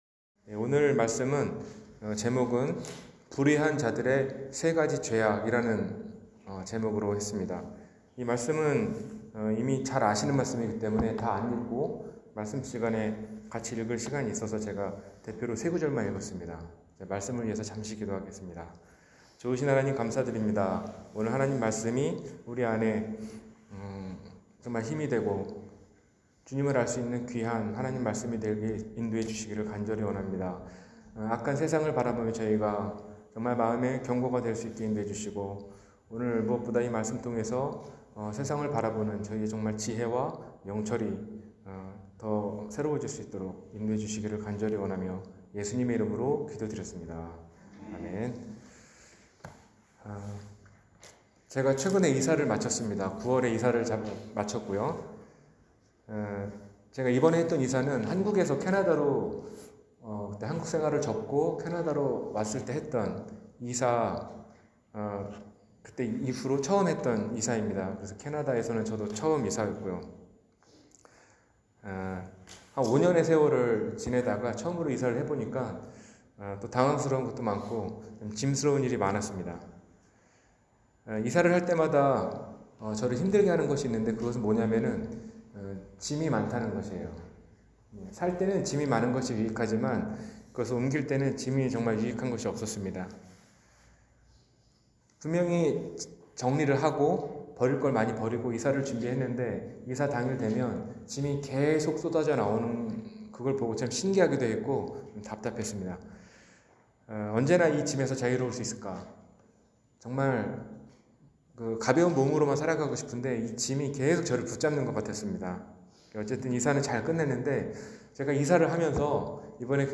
불의한 자들의 세가지 죄악 – 주일설교